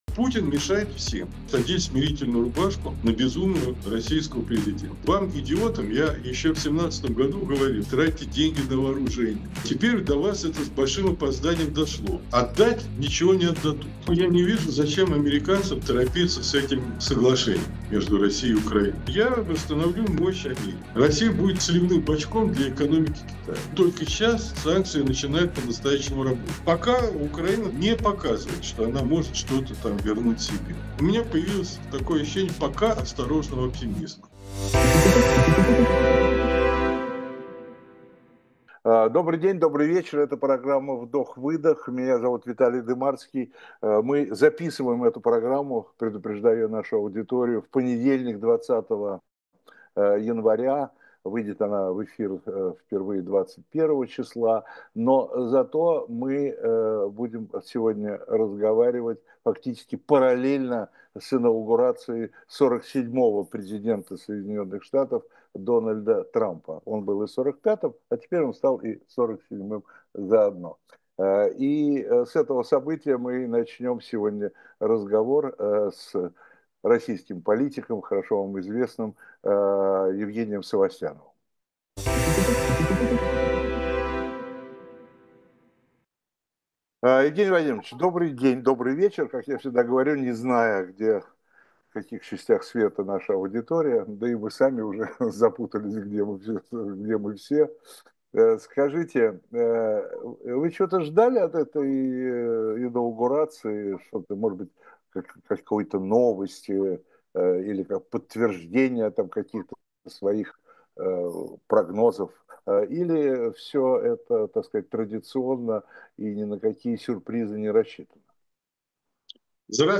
Эфир ведёт Виталий Дымарский